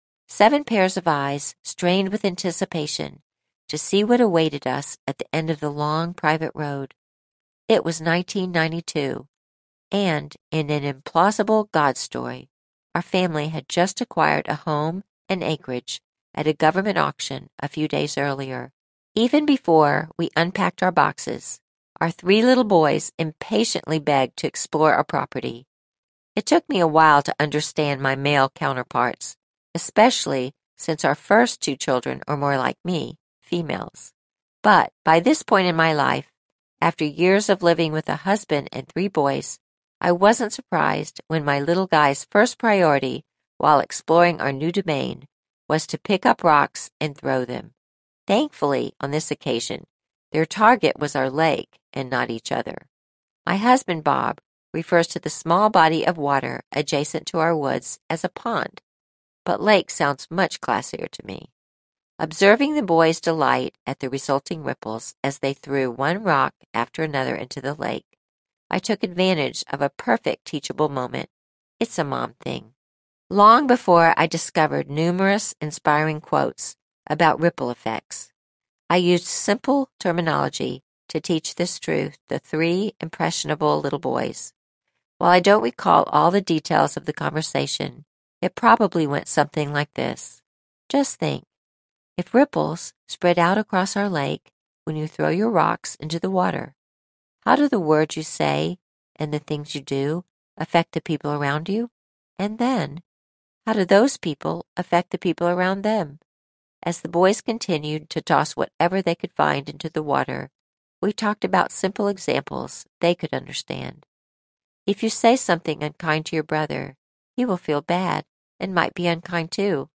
Ripple Effects Audiobook
Narrator